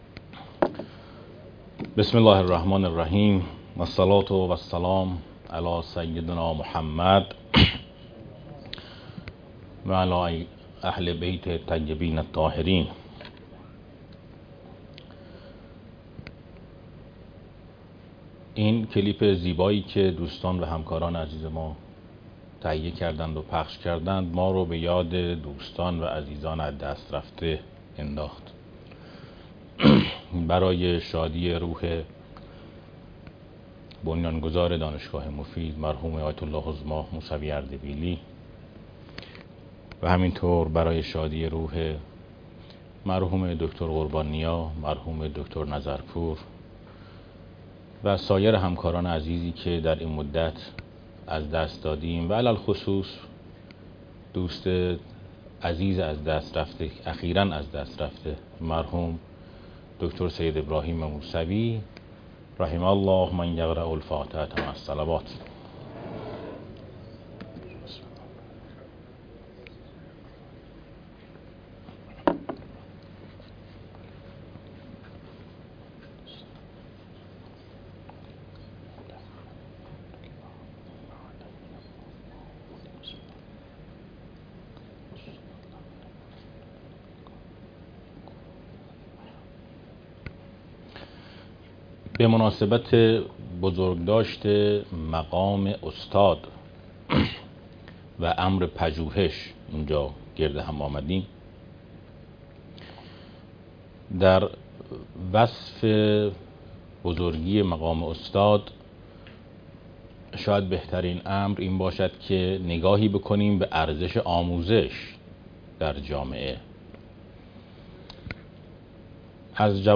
فایل-صوتی-سخنرانی-.mp3